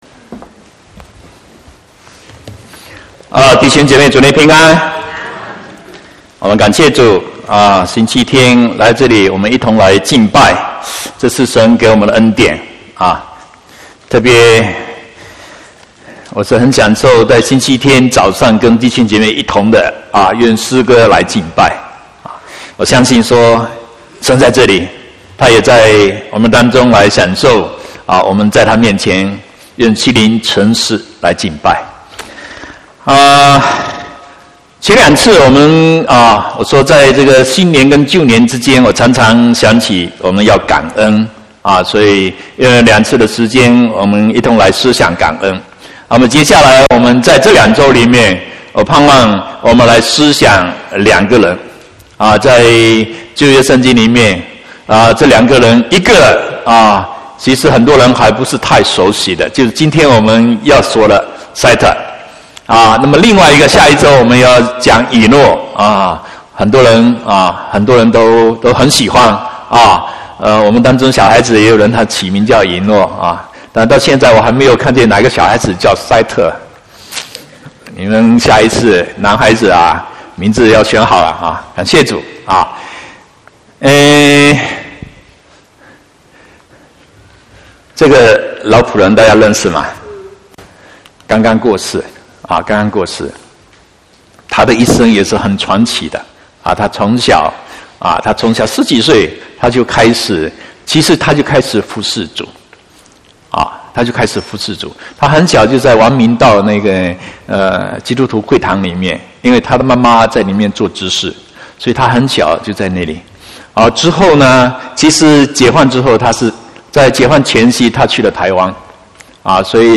11/2/2018 國語堂講道